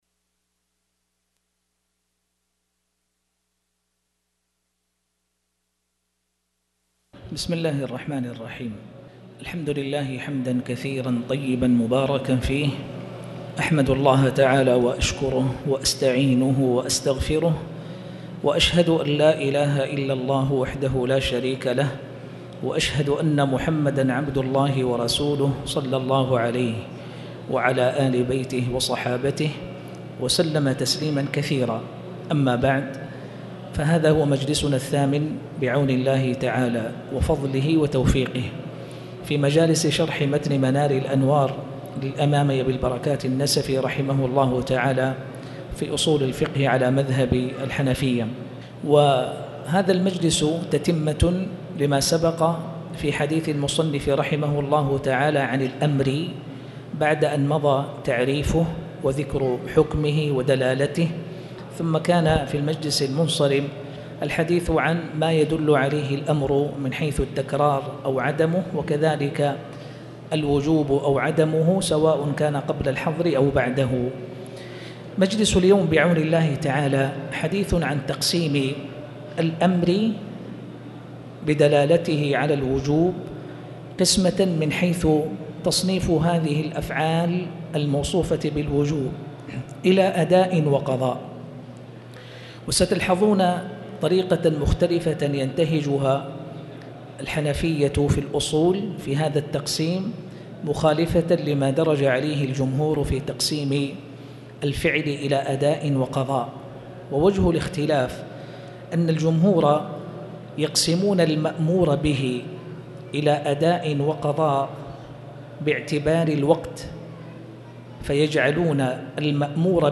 تاريخ النشر ٢٦ صفر ١٤٣٩ هـ المكان: المسجد الحرام الشيخ